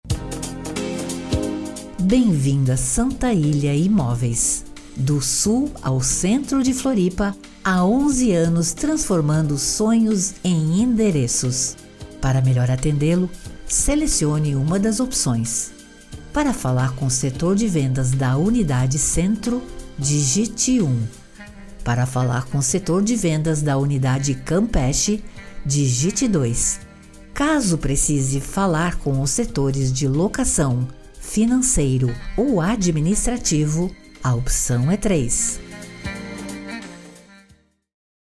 VOZ FEMININA
Santa-Ilha-Centro---URA-de-Atendimento-Marco-25---mp3.mp3